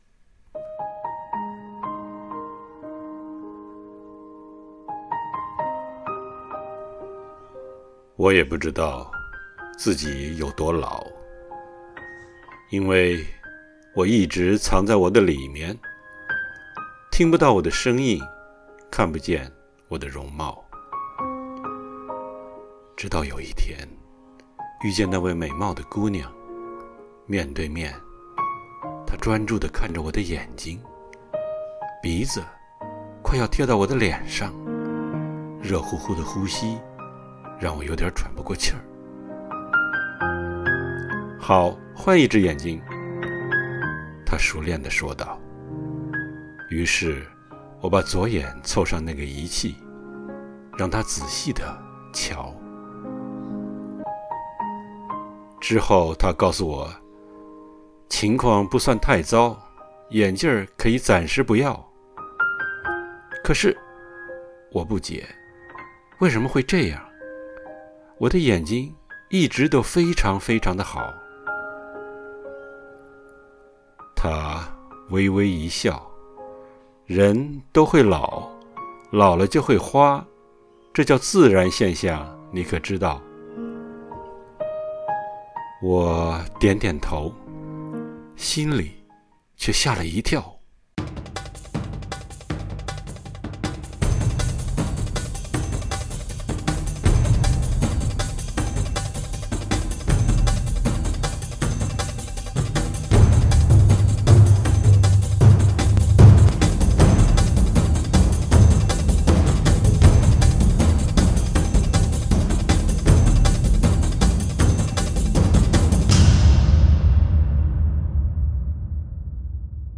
《慢慢变老》朗诵